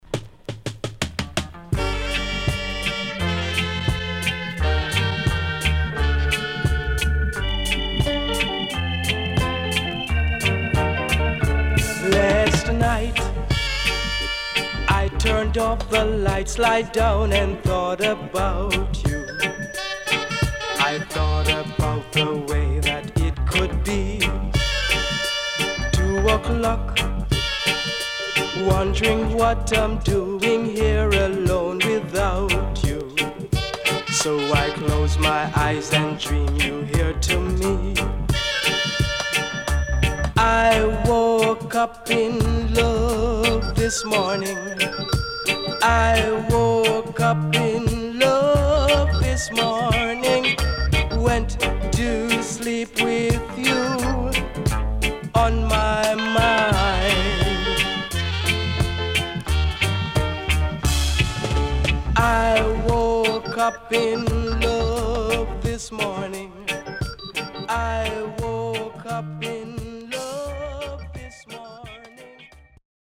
SIDE A:少しノイズ入りますが良好です。
SIDE B:薄くヒスノイズあり、少しノイズ入りますが良好です。